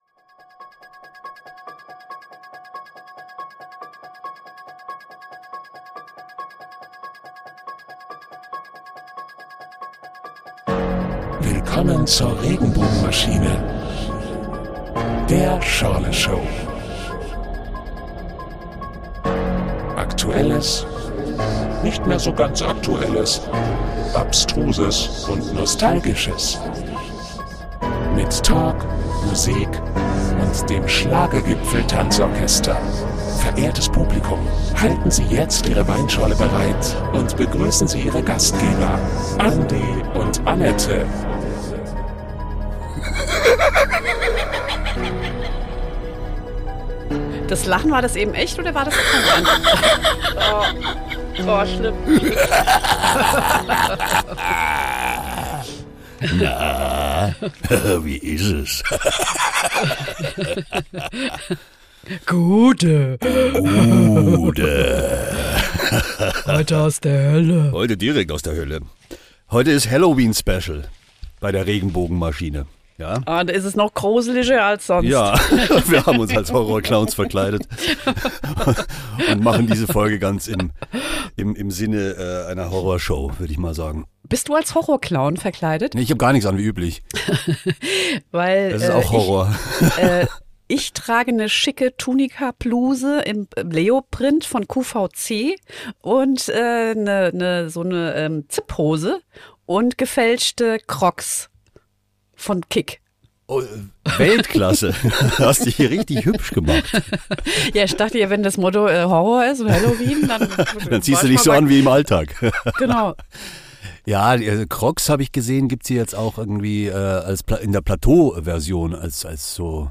Die legendäre Halloween-Folge kehrt zurück – mit allem, was die Nacht des Grauens braucht: spukende Stimmen, bluttriefende Beats und teuflisch gute Geschichten.